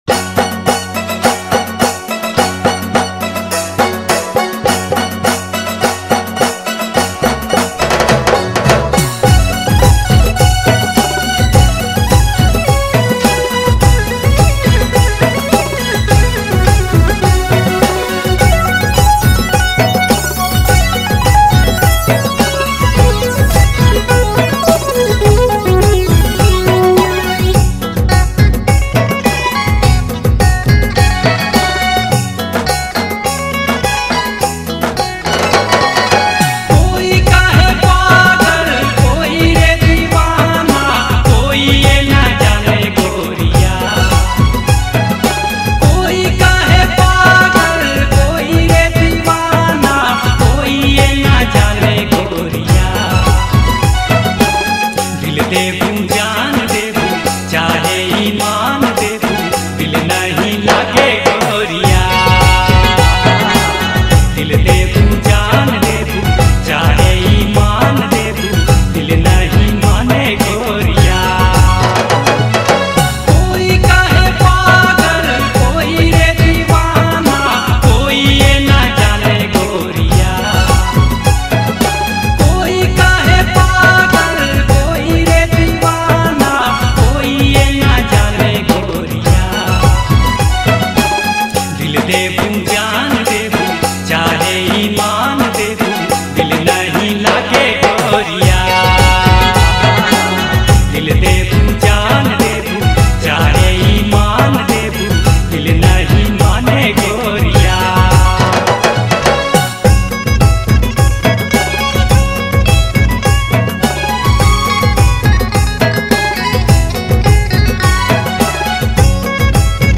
Nagpuri dance song
energetic Nagpuri song.